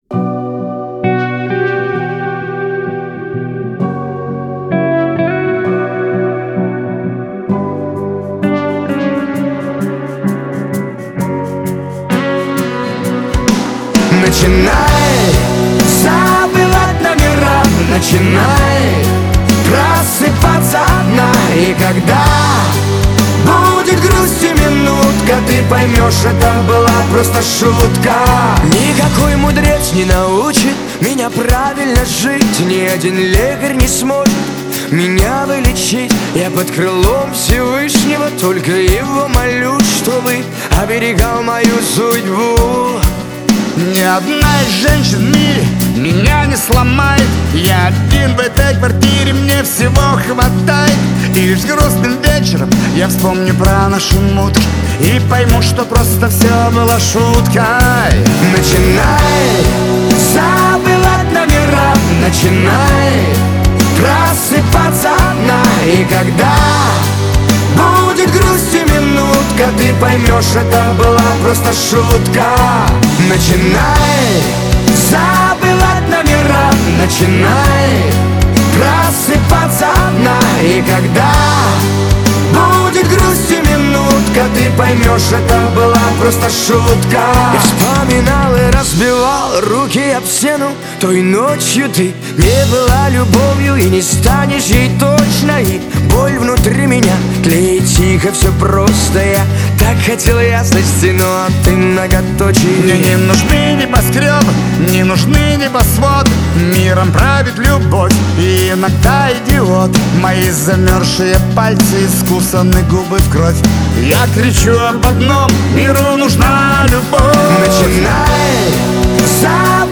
Хип-хоп трек, 3:22.